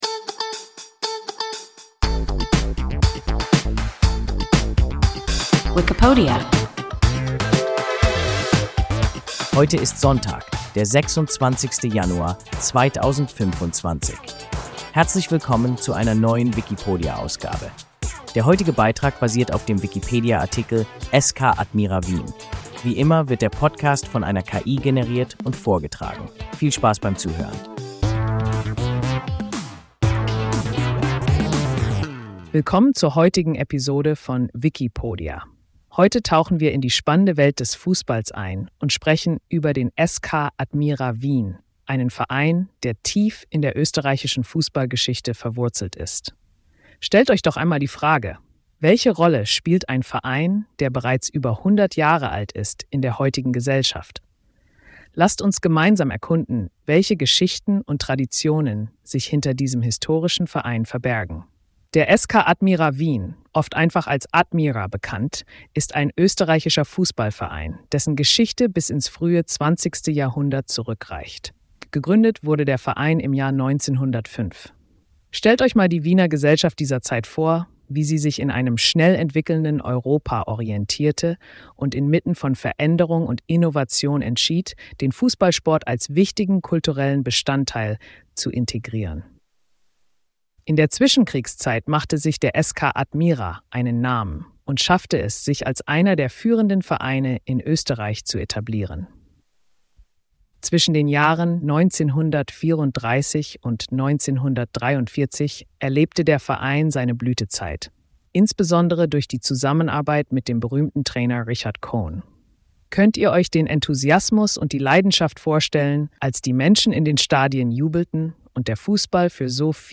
SK Admira Wien – WIKIPODIA – ein KI Podcast